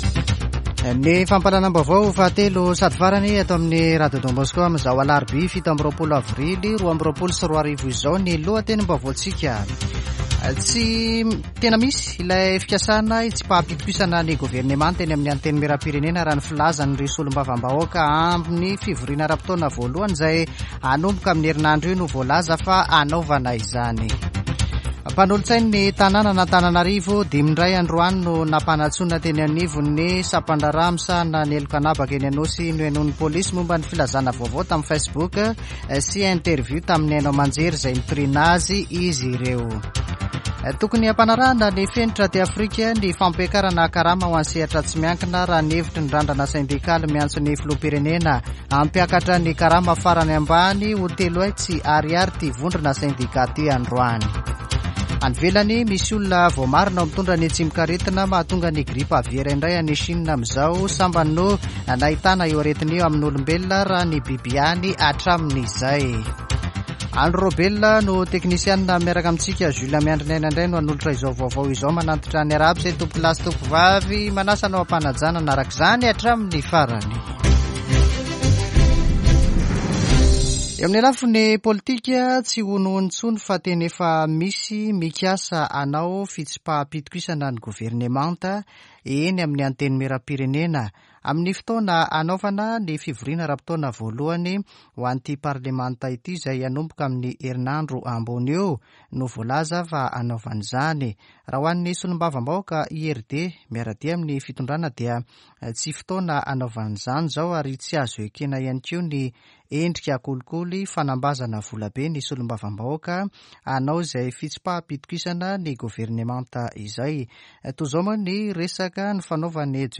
[Vaovao hariva] Alarobia 27 avrily 2022